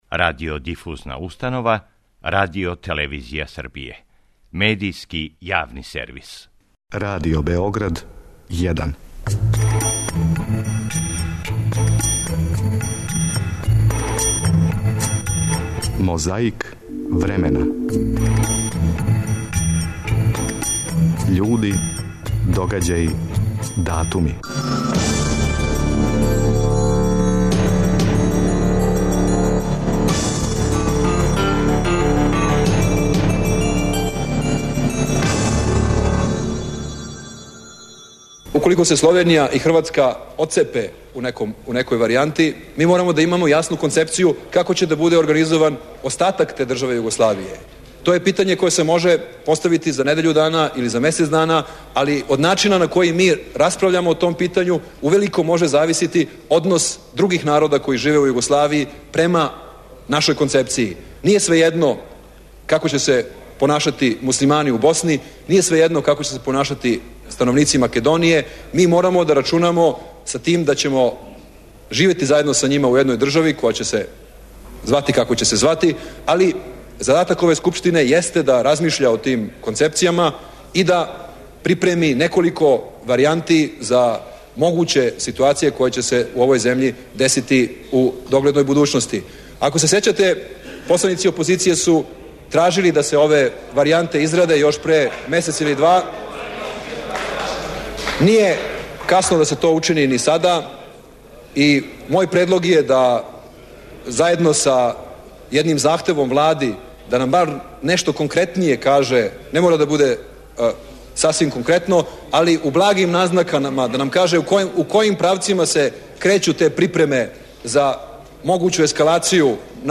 На почетку овонедељне борбе против пилећег памћења подсећамо се како је, 26. јуна 1991. године, говорио посланик Демократске странке, Зоран Ђинђић на заседању Народне скупштине Србије.
Сећамо се како је, тог 26. јуна 1988. године, говорио Али Шукрија. 25. јун 1997.